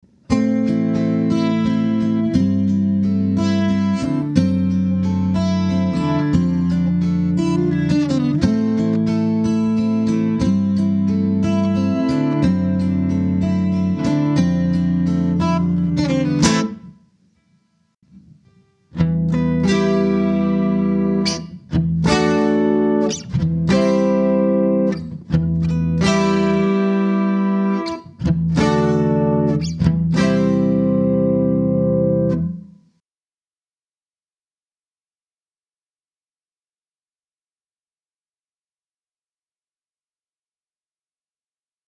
Pour ce test, j’ai simplement enregistré un arpège et une rythmique de chaque acoustique modélisée avant et après la mise à jour.
Guild F212